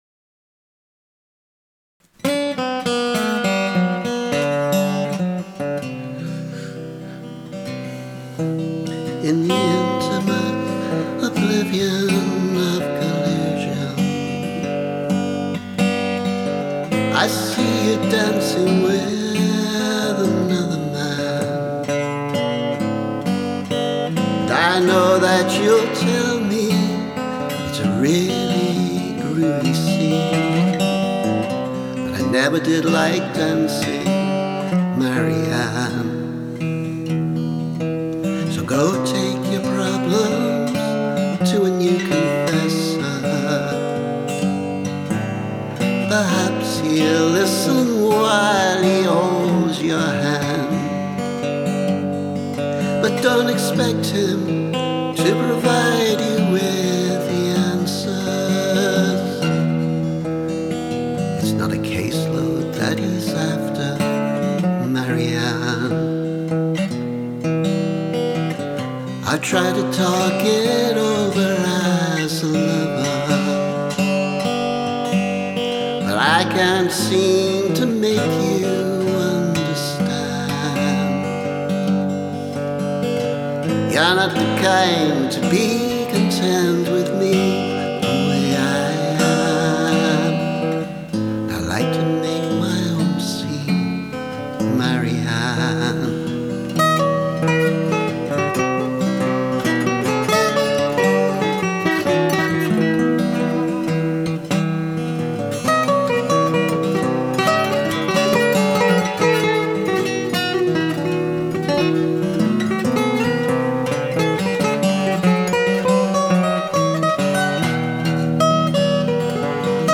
The original tune was later used for something else, so I was kind of making a variation up as I went along on this demo.
Sketch for a better version later, when I finally learn it. Probably one without the out-of-tune lead guitars…